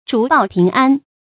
竹報平安 注音： ㄓㄨˊ ㄅㄠˋ ㄆㄧㄥˊ ㄢ 讀音讀法： 意思解釋： 指平安家信。